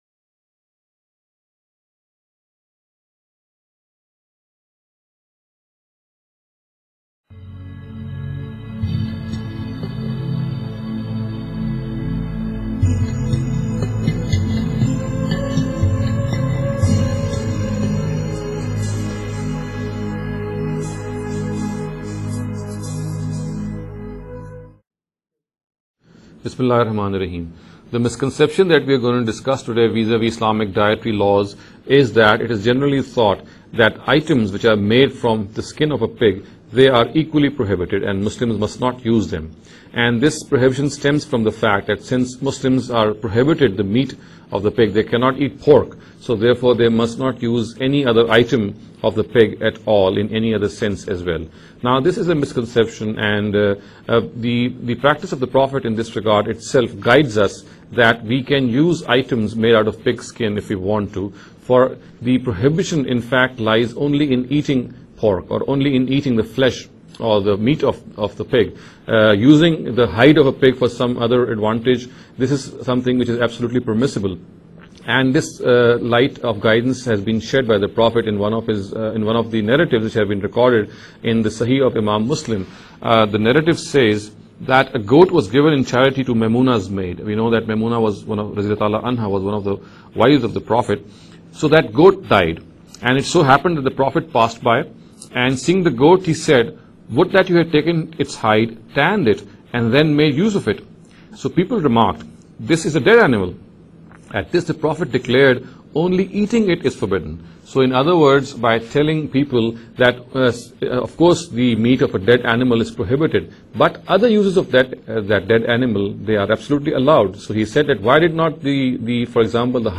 This lecture series will deal with some misconception regarding The Dietary Directives of Islam. In every lecture he will be dealing with a question in a short and very concise manner. This sitting is an attempt to deal with the question 'Can Items Made of Pig Skin be used?’.